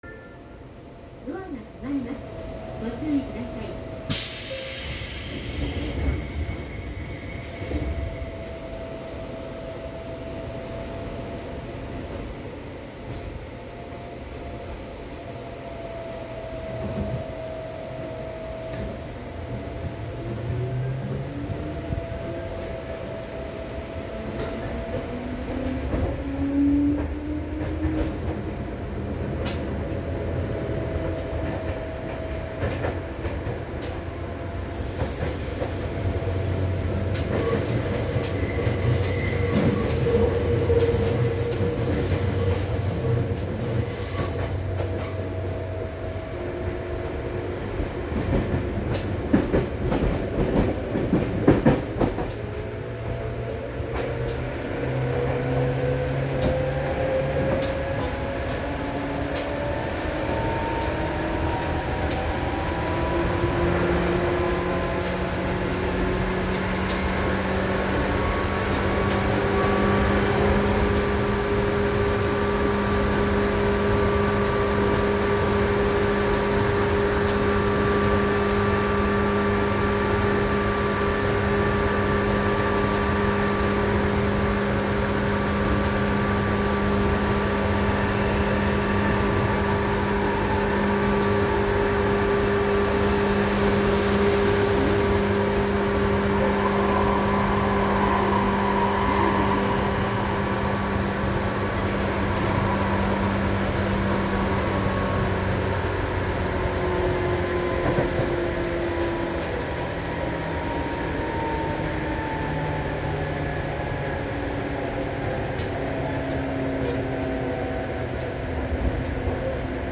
・新101系走行音
【多摩湖線】武蔵大和→八坂（3分12秒）
ＪＲで言ったところの103系によく似た音…というより同じ音です。
101_MusashiYamato-Yasaka.mp3